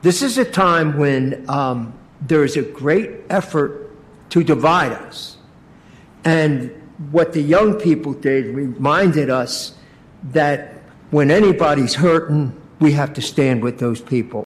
Commissioner Don Cooney says what they are great, particularly at this time in history.